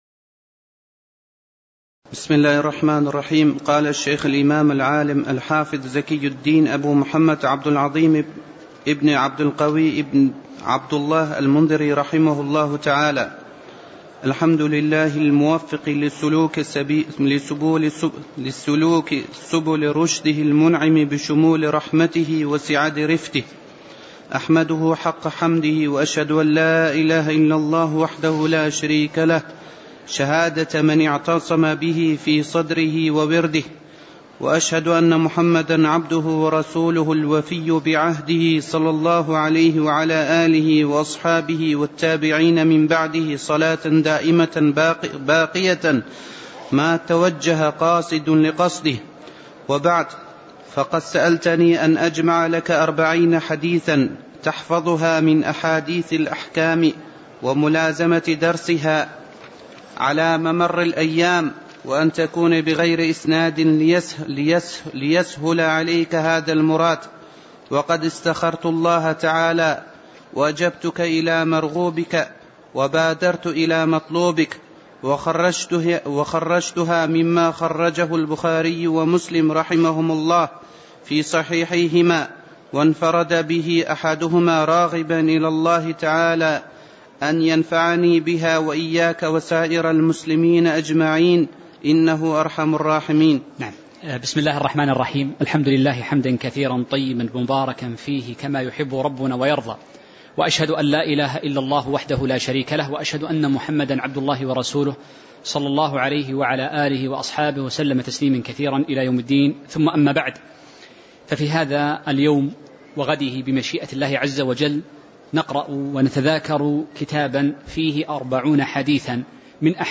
تاريخ النشر ٢٤ ربيع الثاني ١٤٣٩ هـ المكان: المسجد النبوي الشيخ